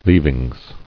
[leav·ings]